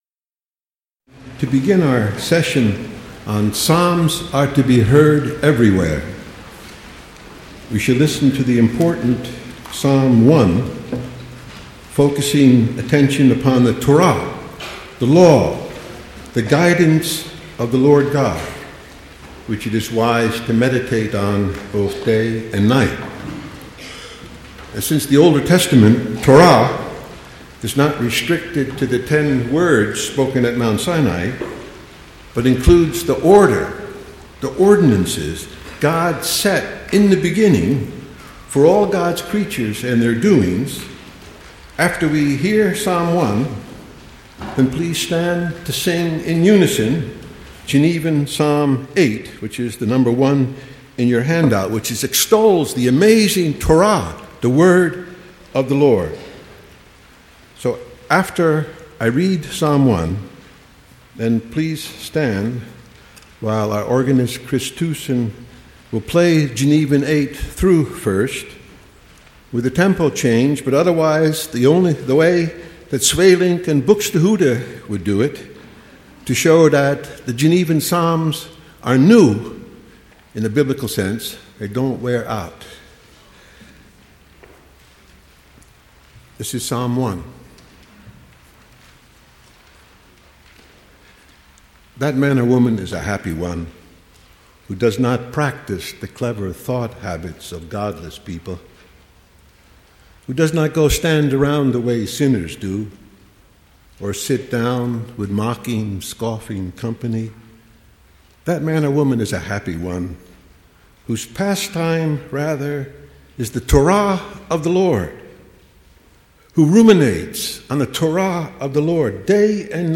Presented at the 2005 Symposium on Worship.